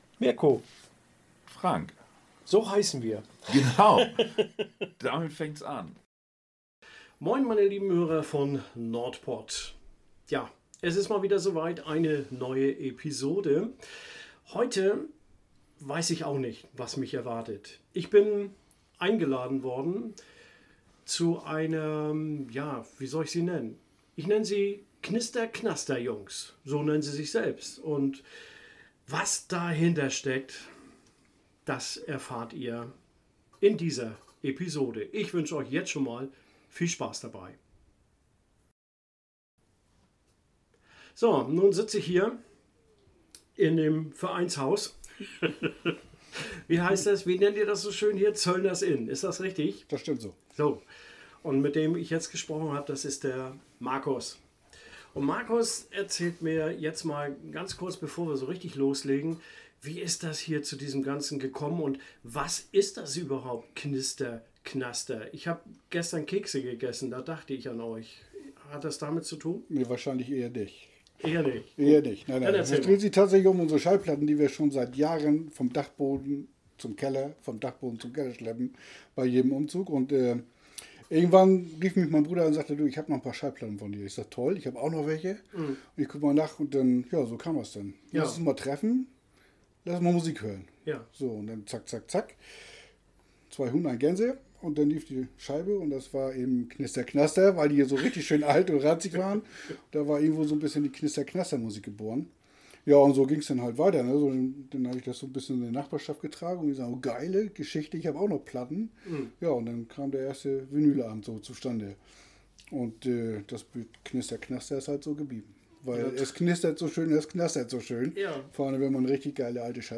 Knister Knaster ist ein kleiner Verein, in dem sich meist 6 Männer treffen! Sie treffen sich in einer Gartenlaube, die sie "Zöllners Inn" getauft haben! Jeder der "Jungs" bringt eine Schallplatte (Vinyl) mit und eine Geschichte dazu!